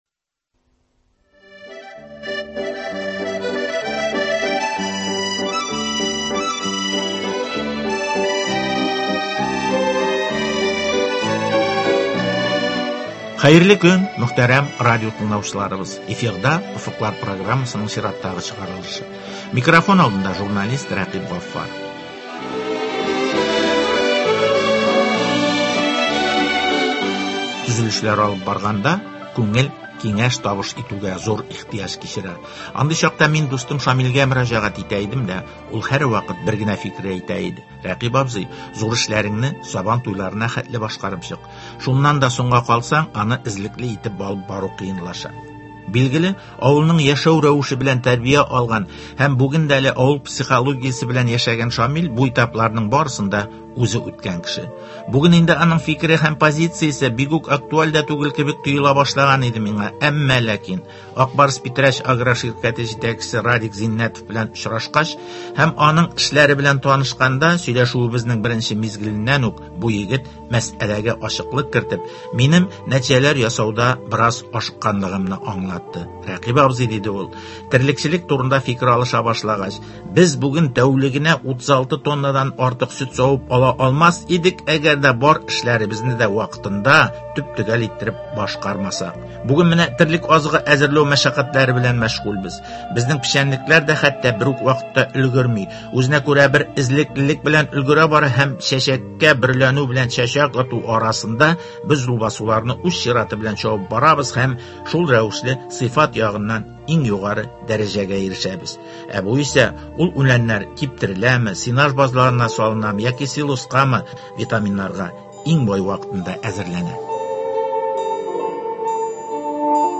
эшне оештыруның яңа формаларын куллану мәсьәләләренә багышланган әңгәмә.